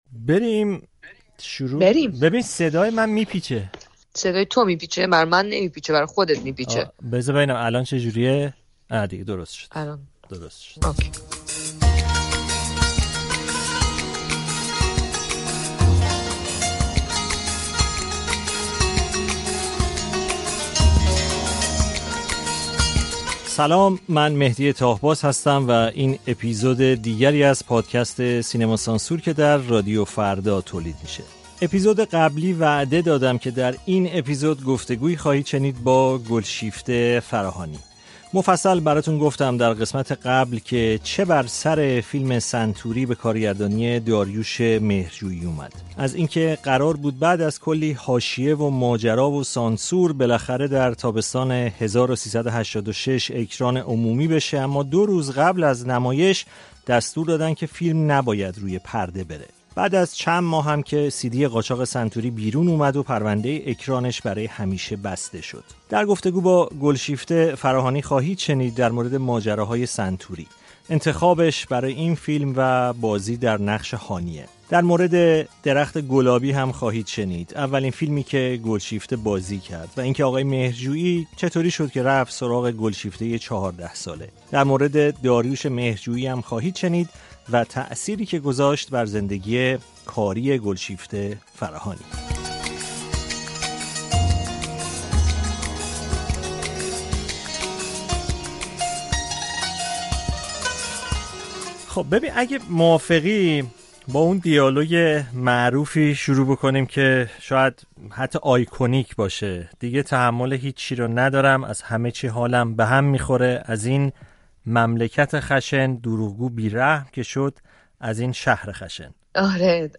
از عبای سنتوری تا قتل داریوش مهرجویی؛ گفت‌وگو با گلشیفته فراهانی